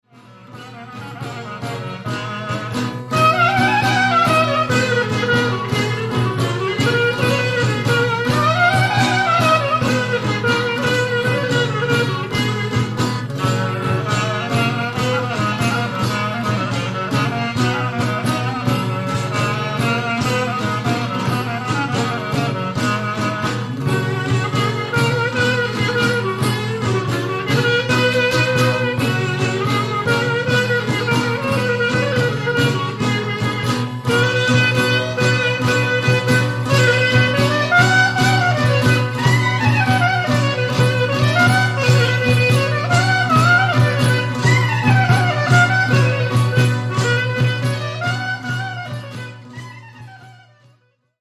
The meter is a very interesting 7/8 and 5/8 combination.